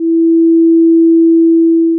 Sound 4 – Sinusoid 330Hz
3-330Hz-2s.wav